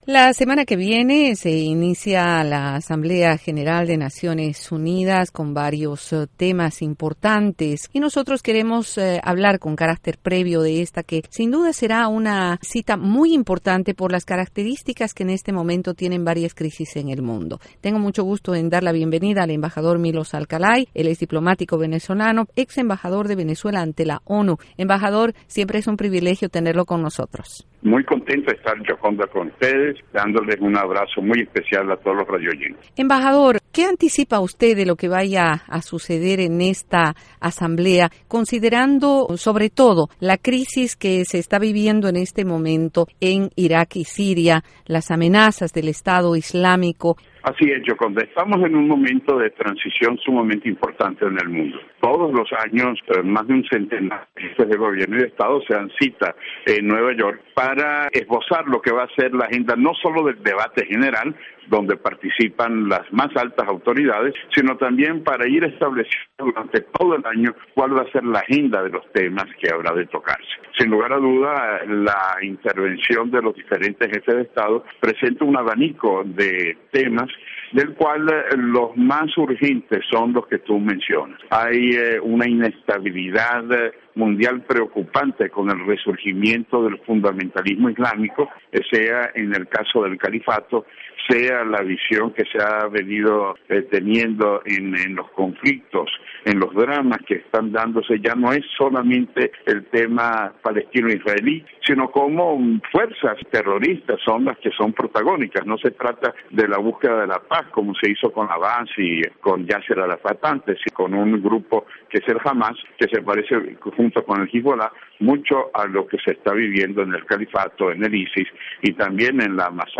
Entrevista a Milos Alcalay, ex embajador de Venezuela en la ONU